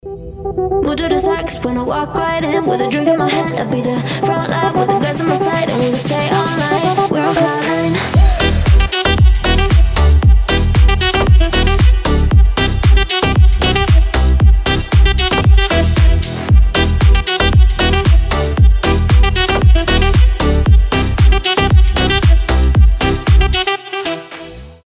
зажигательные
house
динамичные
Позитивный клубняк на звонок